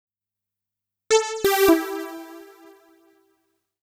XTRA031_VOCAL_125_A_SC3.wav